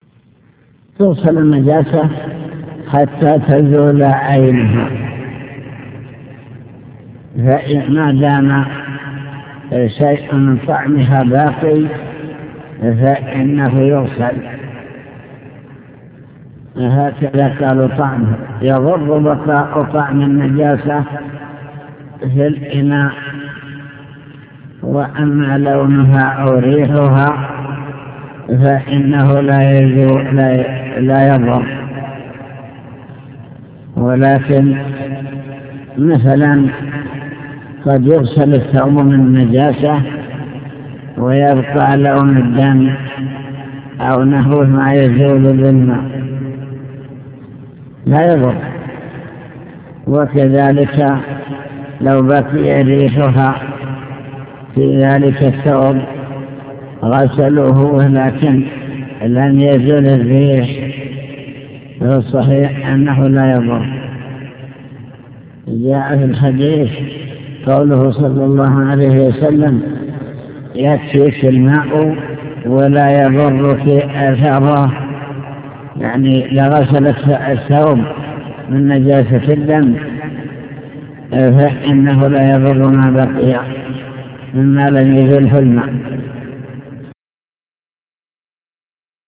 المكتبة الصوتية  تسجيلات - كتب  شرح كتاب دليل الطالب لنيل المطالب كتاب الطهارة باب إزالة النجاسة